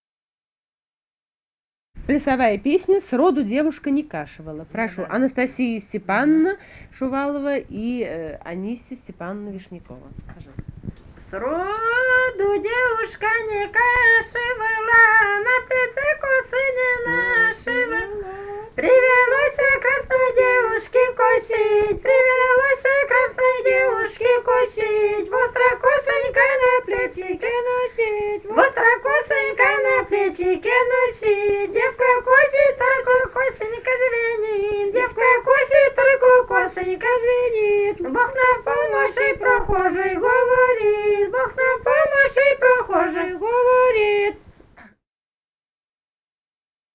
Этномузыкологические исследования и полевые материалы
Архангельская область, с. Долгощелье Мезенского района, 1965, 1966 гг.